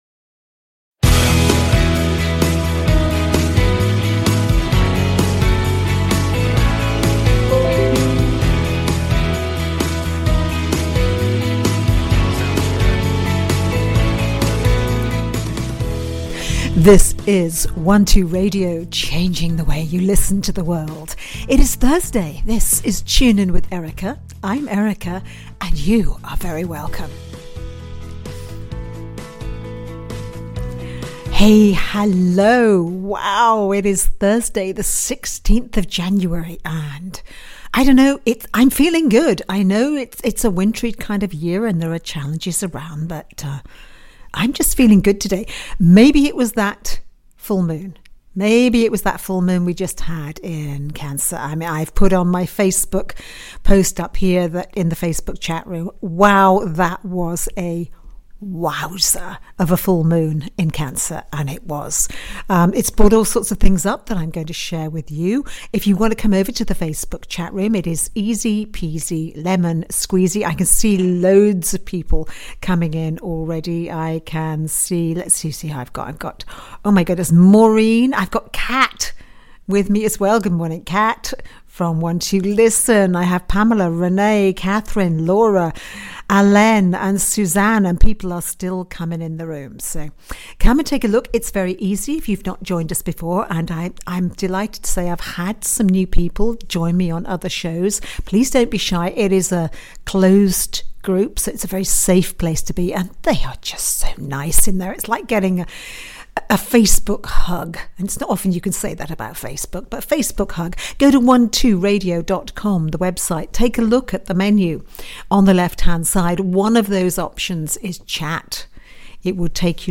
Dragon meditation.mp3